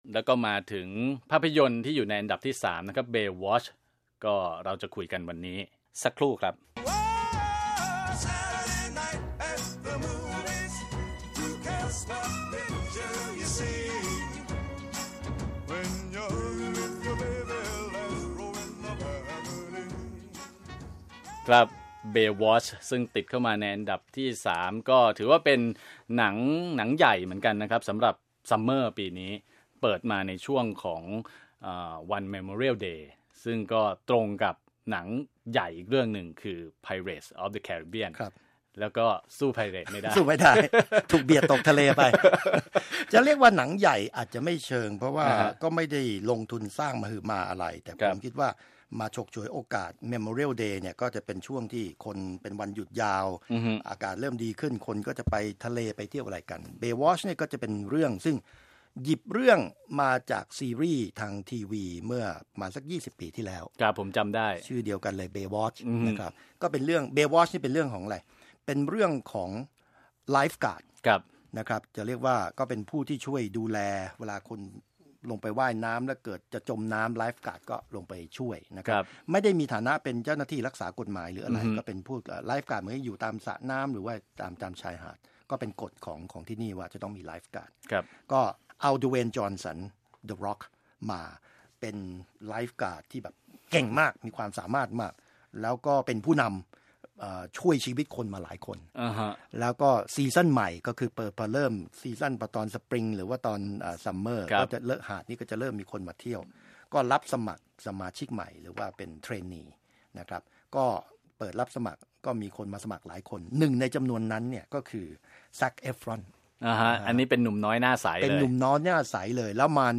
วิจารณ์ภาพยนตร์